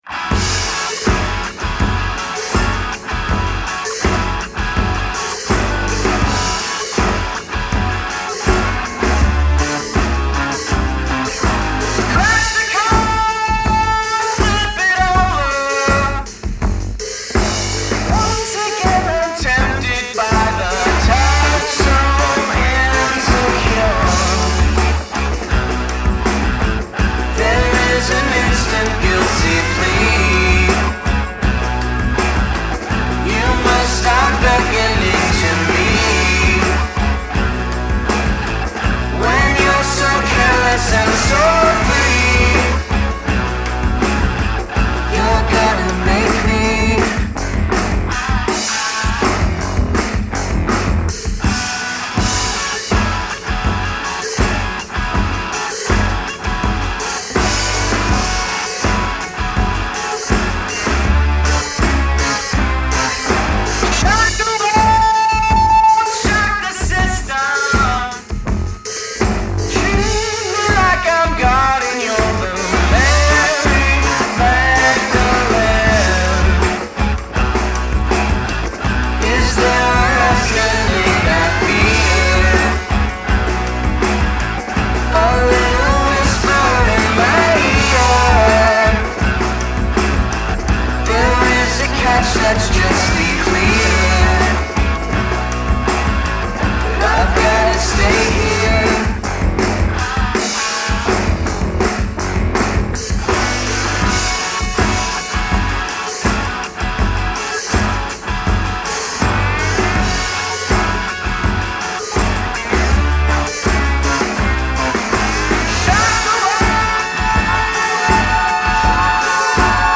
American indie rock band with a punk edge to their music
something of a 2000s indie sound to their music